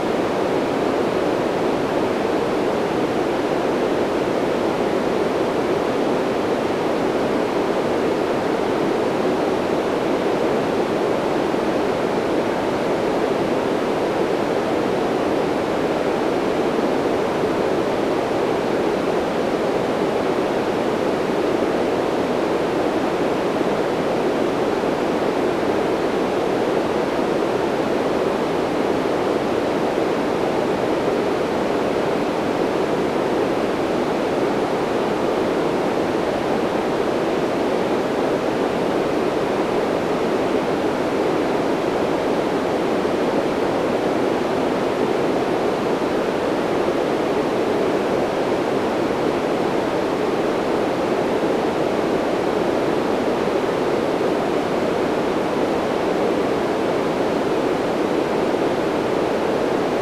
That YouTube video (v=nHJVDAkLS10) is not really white noise: it has resonance ~500Hz /uploads/default/original/3X/c/3/c31c3d47a5d44de7b3867fdf378051f36a54f9a9.flac [emulation without hum. Loopable]